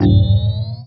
power_on.wav